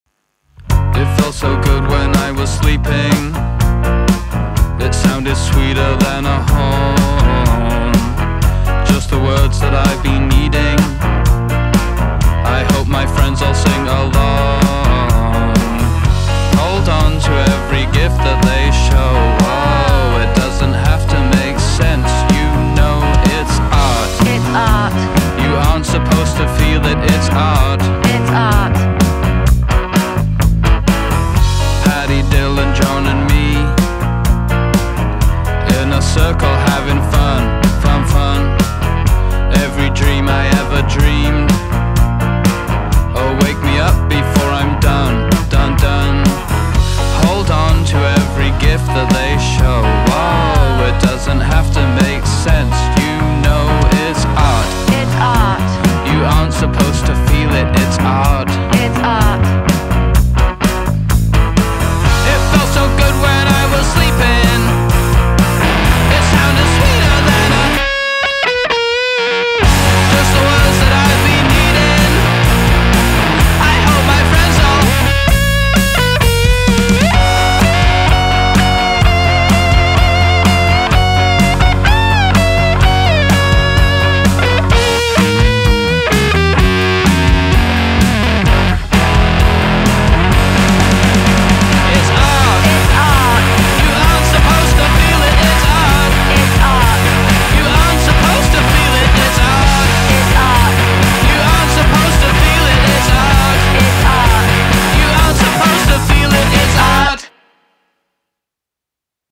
Fast songs. Faster songs. Rockin’. Rowdy’. Quirky. Quaint.